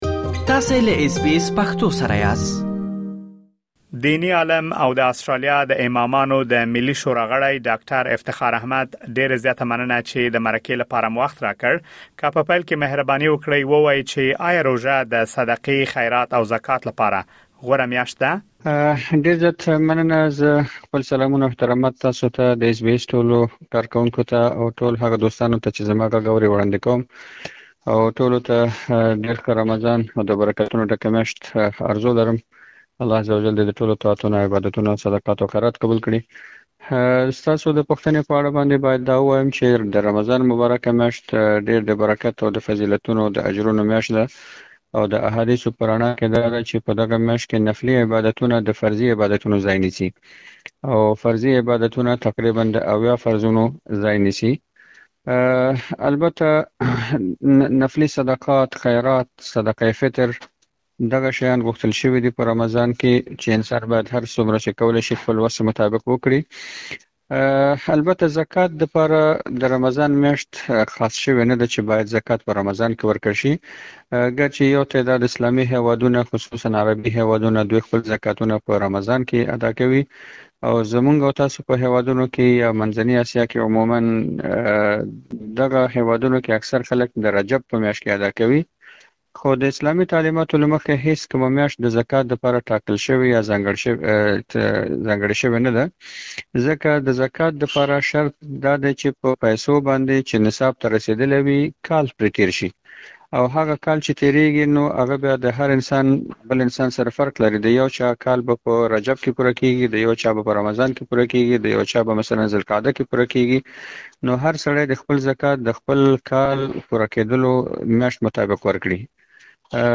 مهرباني وکړئ لا ډېر معلومات په ترسره شوې مرکې کې واورئ.